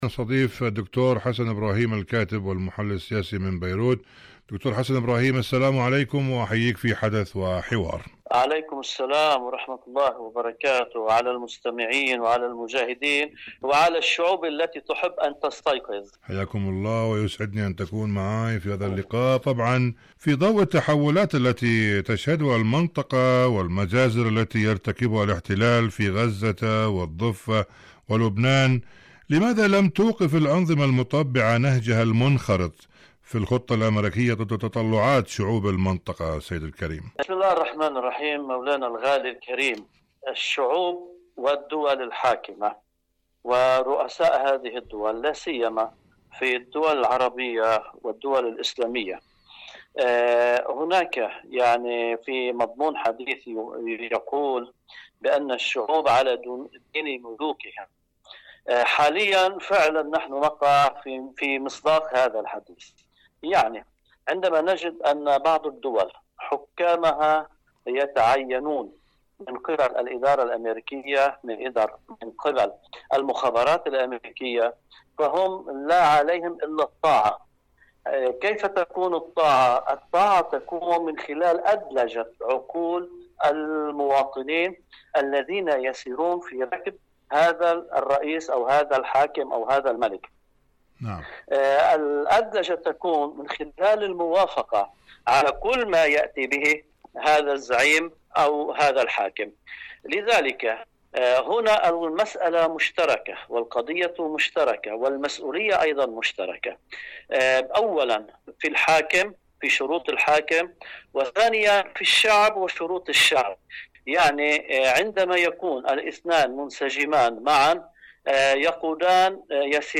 مقابلات إذاعية